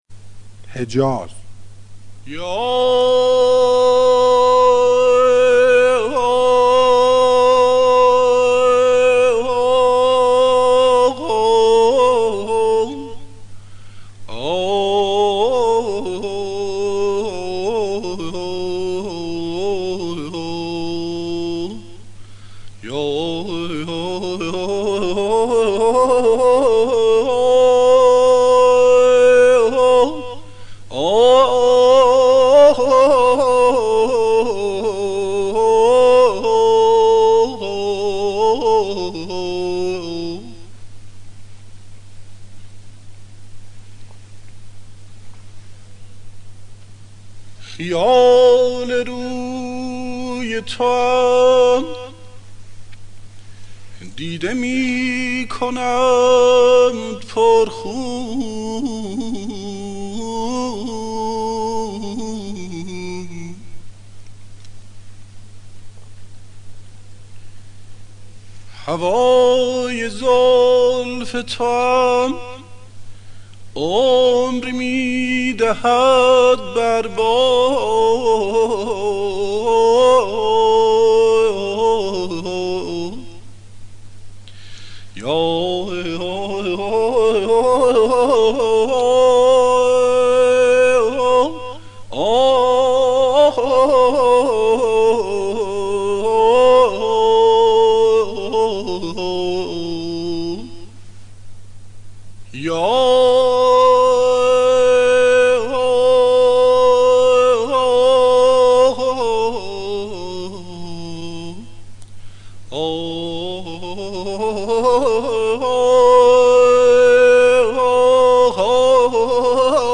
دانلود آموزش ردیف آواز اصیل ایرانی به روایت استاد کریمی – ابوعطا – مجله نودیها
اشتراک گذاری : رادیو بصیرت – موسیقی سنتی در ادامه آموزش ردیف آوازی موسیقی اصیل ایرانی به روایت استاد کریمی ، آواز ابوعطا و گوشه های آن ارائه می شود.